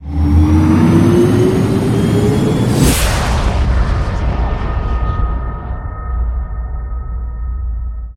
ships / movement / launch4.ogg
launch4.ogg